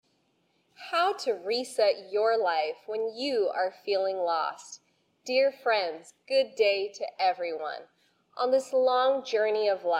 talk_female_english_10s.MP3